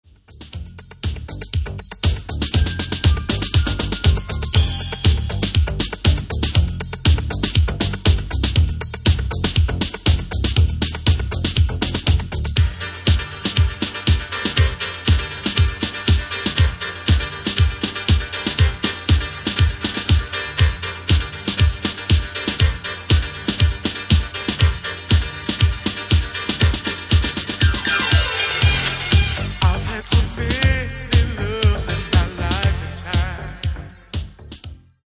Genere:   Disco | Soul | Funk